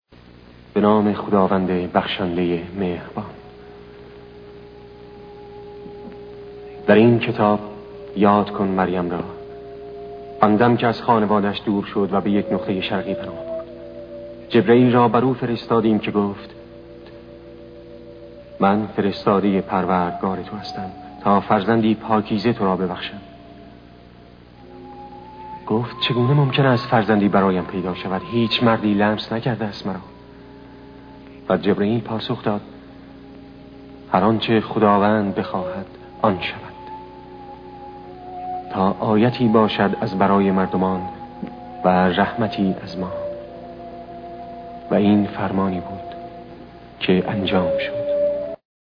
جلال مقامی در این فیلم صدای نویل جیسون را در نقش جعفربن ابی‌طالب دوبله می‌کرد.